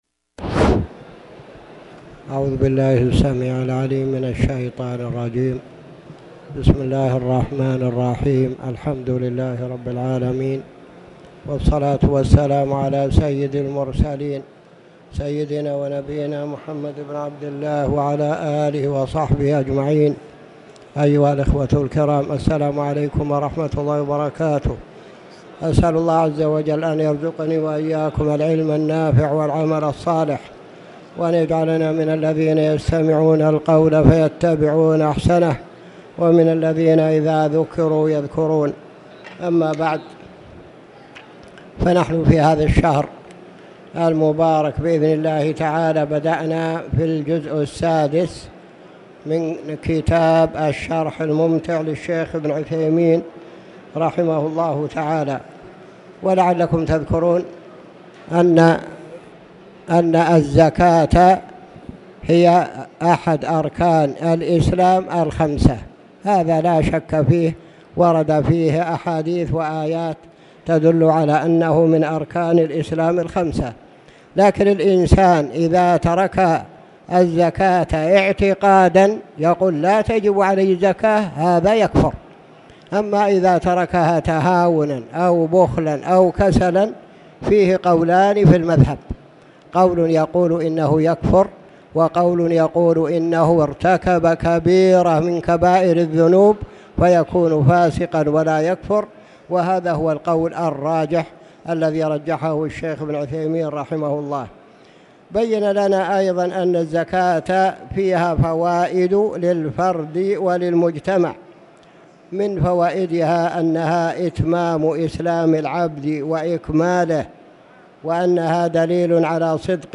تاريخ النشر ٧ جمادى الآخرة ١٤٣٨ هـ المكان: المسجد الحرام الشيخ